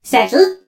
pearl_hurt_vo_03.ogg